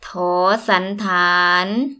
v toor ∨ sann ∨ taan
toor-sann-taan.mp3